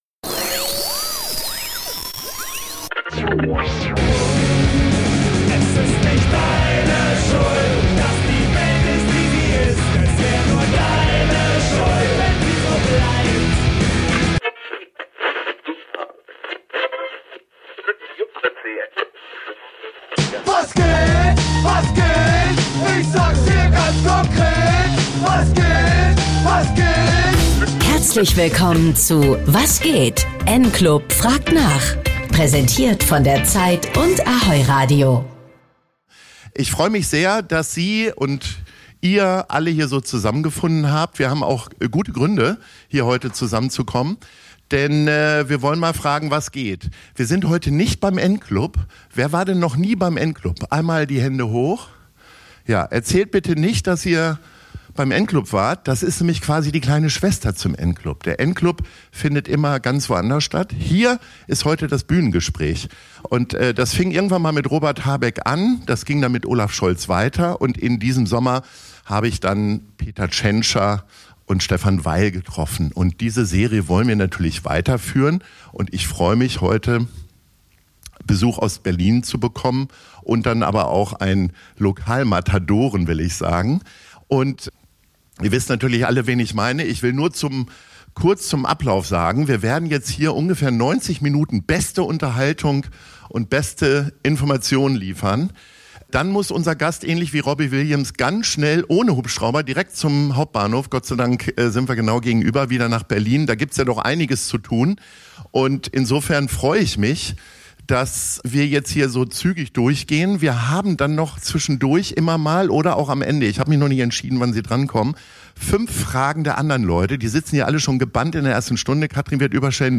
Den Mitschnitt des spannenden wie überraschenden Gesprächs hört ihr hier in voller Länge.